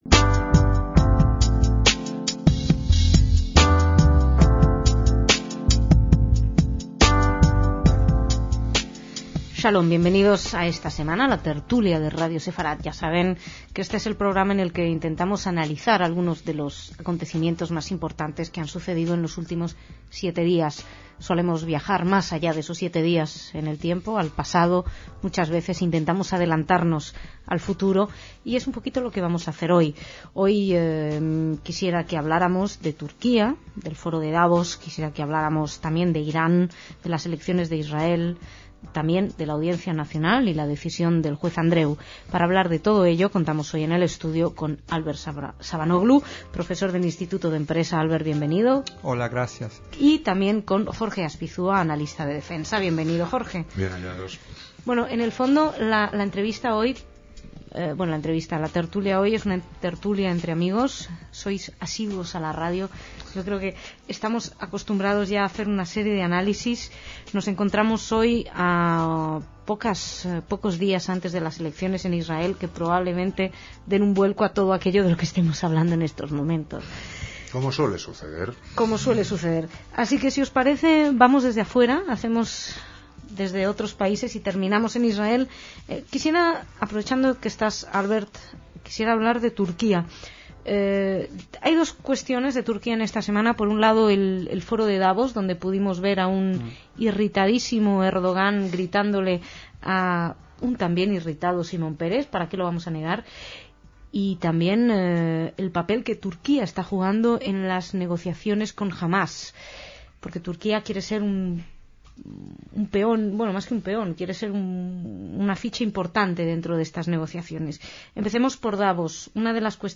Dos contertulios habituales